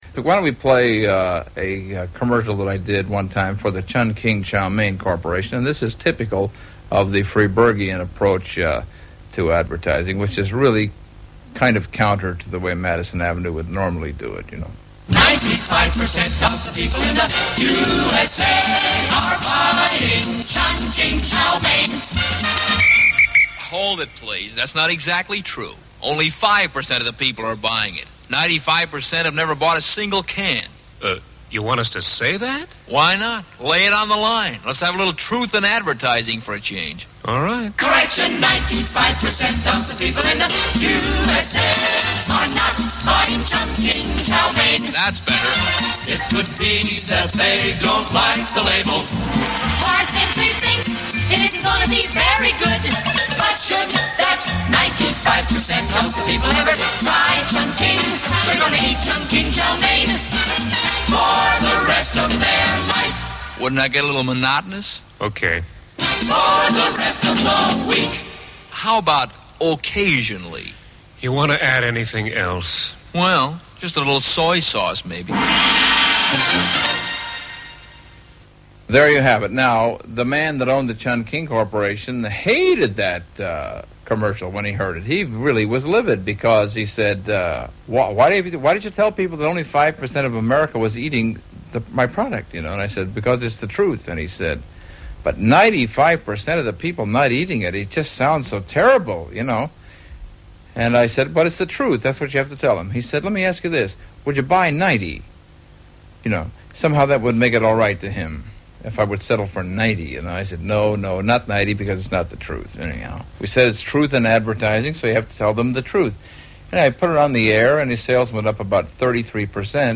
Here US Comedian Stan Freberg recalls how he tried to introduce truth into advertising, much to the concern of the client, with this infamous Chun King Chow Mein Corporation commercial.